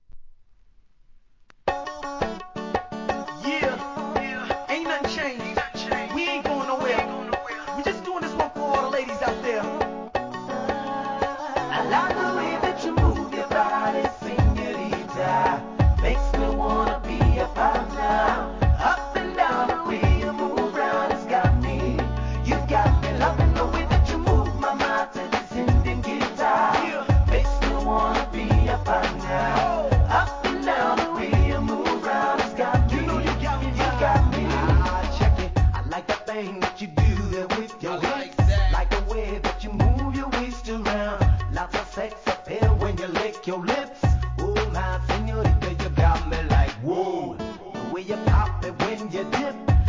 REGGAE
R&B調に仕上げた2004年作品!